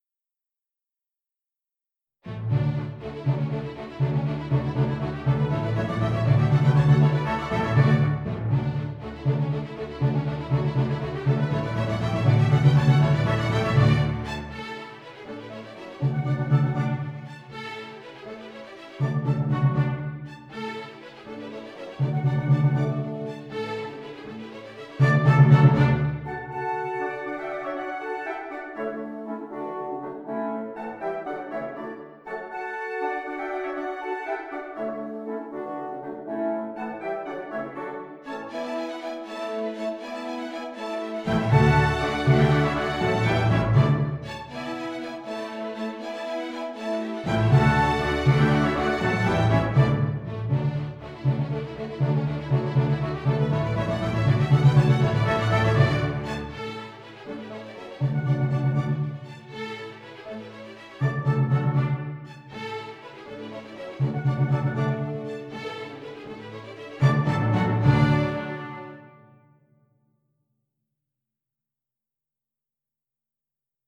I did now the same with the first 5 dances on the basis of these old midi files by simplifying them a bit and using my tool NotePerformer.
My renditions may have too much reverb, should I reduce the hall effect?
WoO13-1_orch.mp3